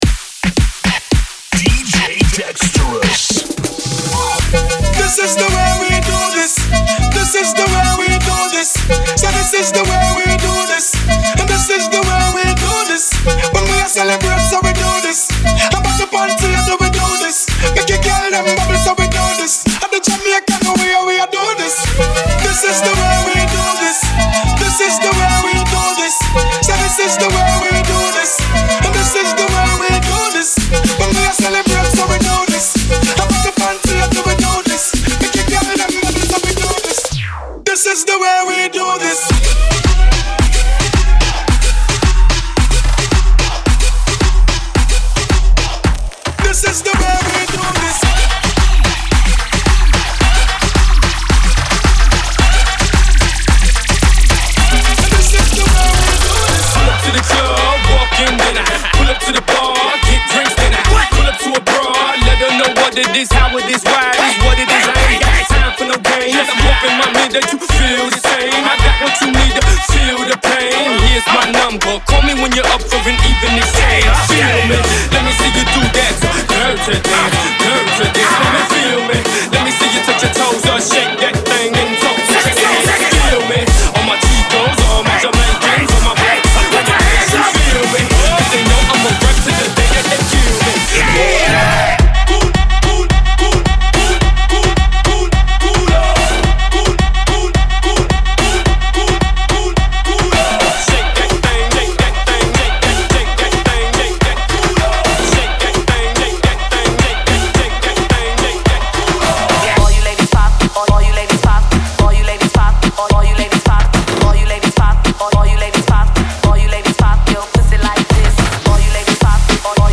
High energy mixing and scratching